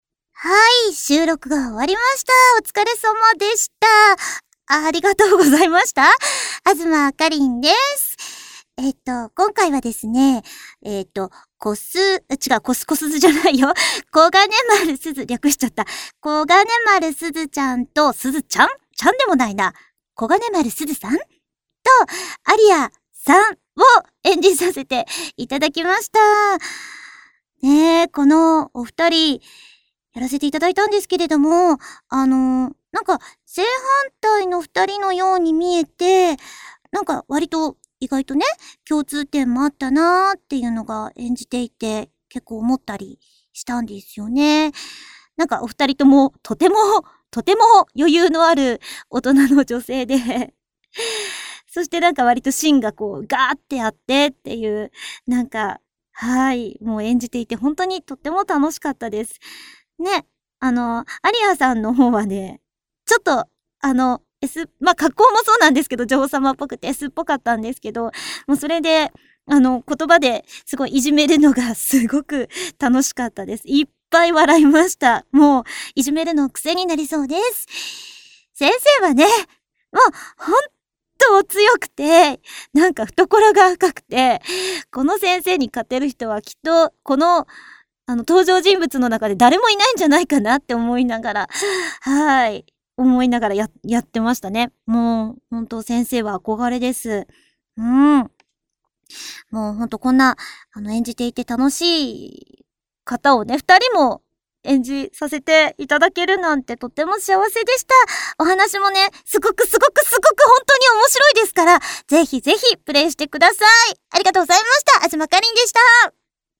さてさて、声優さんからコメントを頂いております。